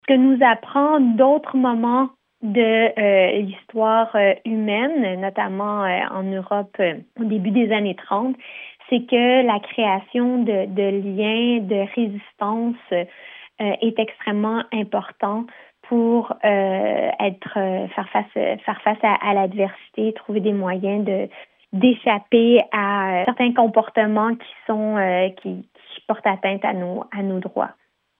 L’écosociologue et coinstigatrice de Mères au front, Laure Waridel, explique que ce mouvement de solidarité est plus qu’essentiel dans le contexte géopolitique actuel :